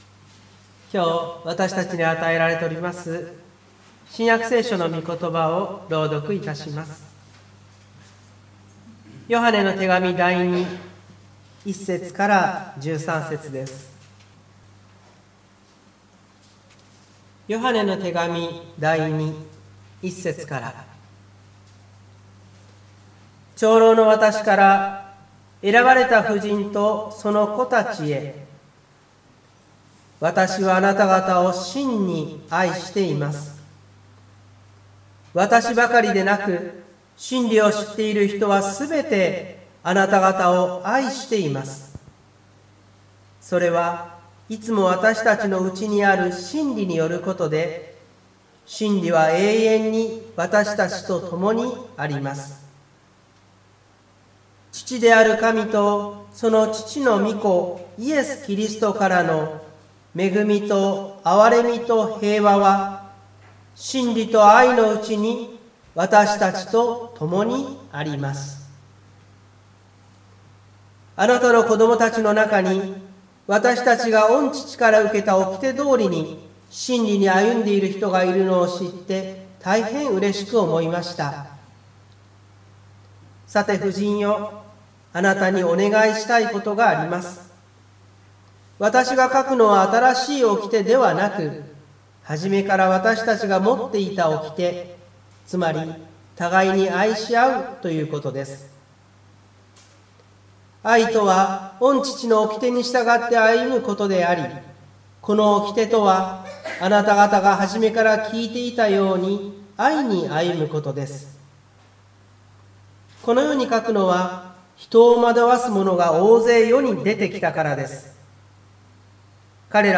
湖北台教会の礼拝説教アーカイブ。
日曜朝の礼拝